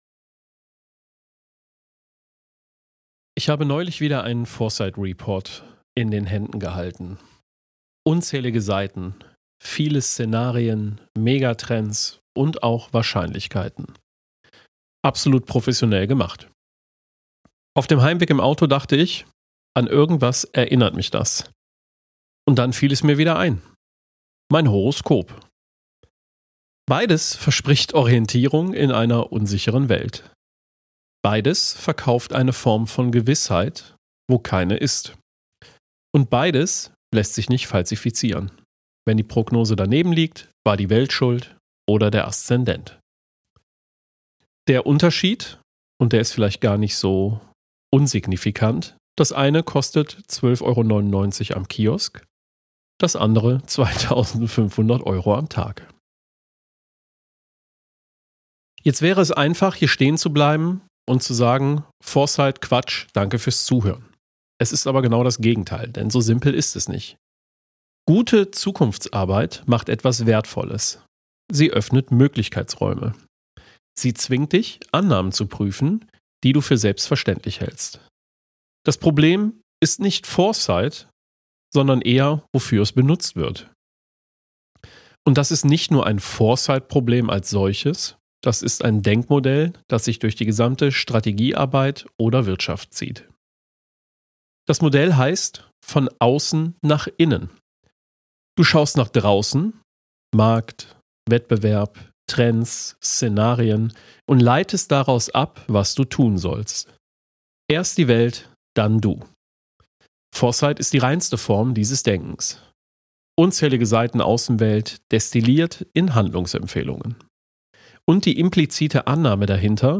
Kein Jingle, kein Small Talk.